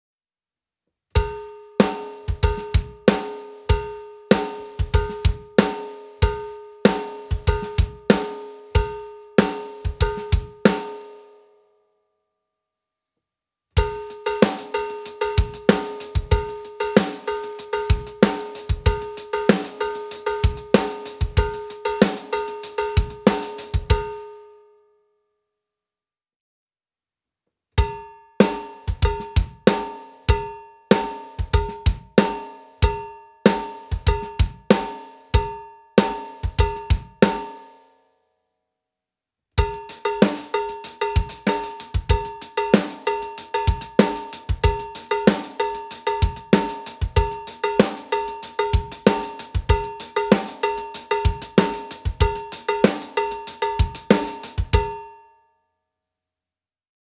Made from black powder coated steel, the MEINL Headliner® Series Cowbells produce a cutting and sharp sound.